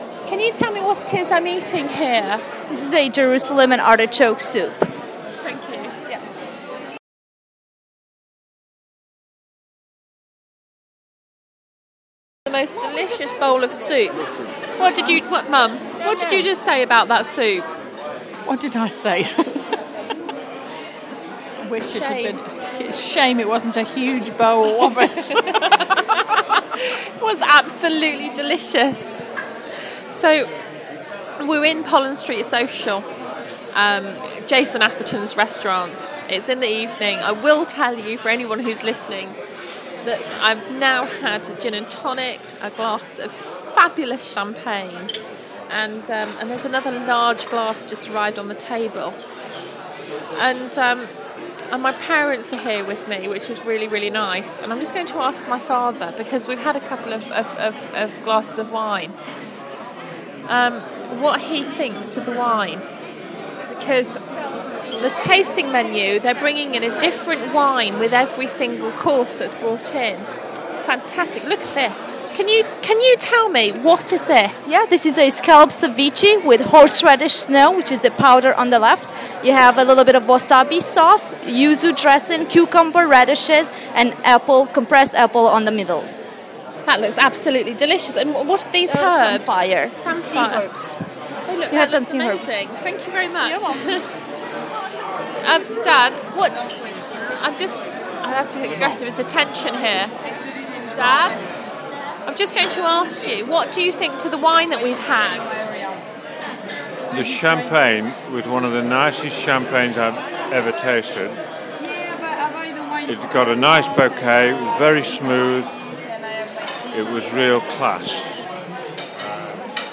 Eating a meal at Pollen street Social